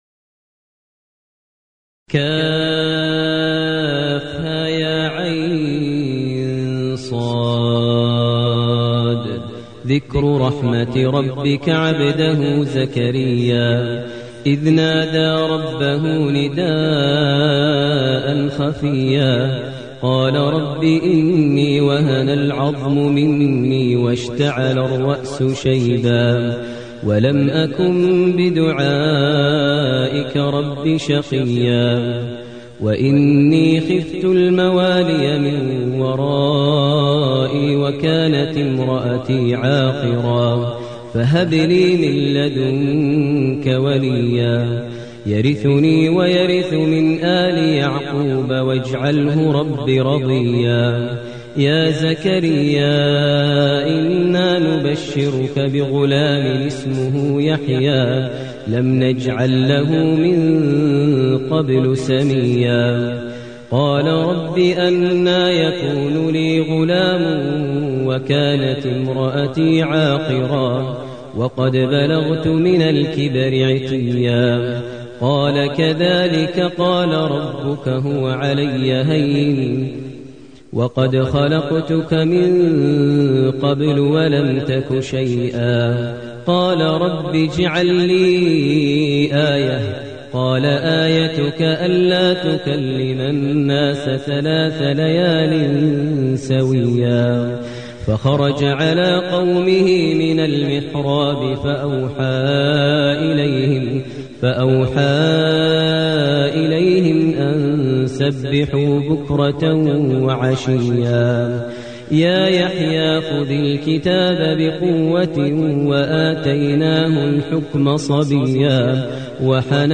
المكان: المسجد الحرام الشيخ: فضيلة الشيخ ماهر المعيقلي فضيلة الشيخ ماهر المعيقلي مريم